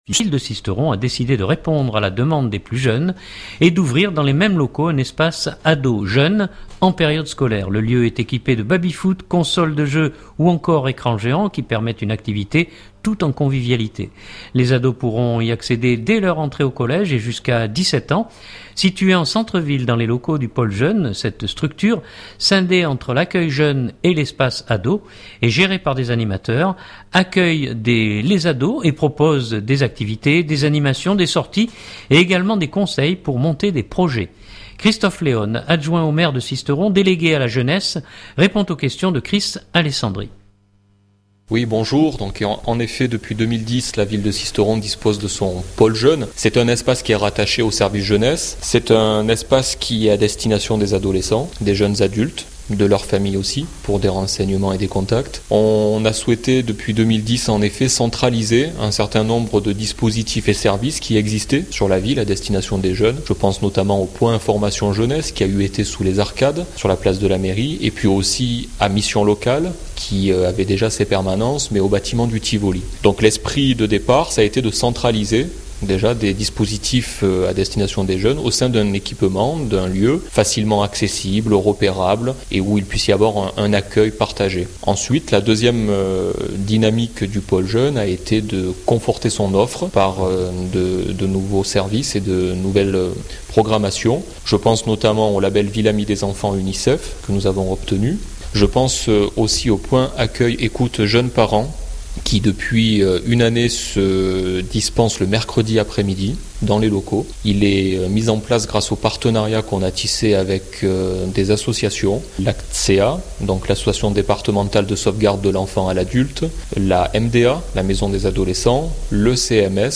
Christophe Léone, adjoint au maire de Sisteron délégué à la jeunesse répond aux questions